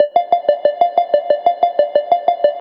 FK092SYNT1-R.wav